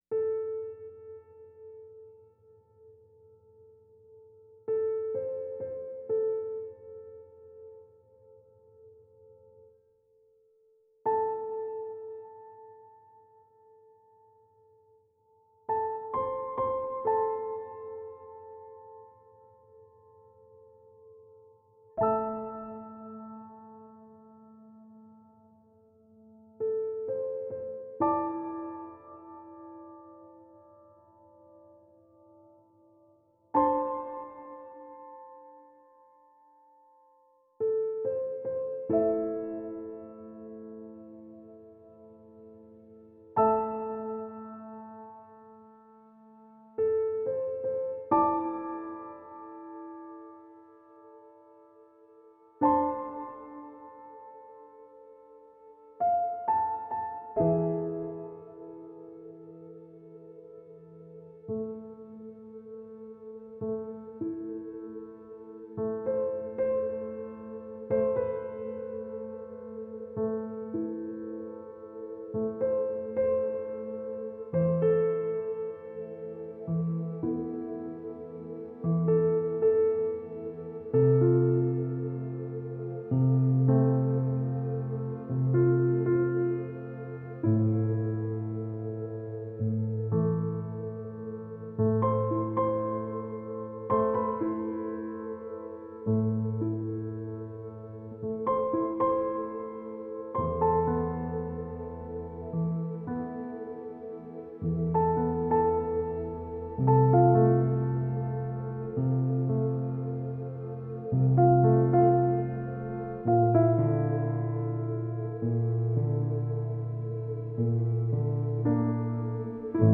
واقعا عالی و حماسی بود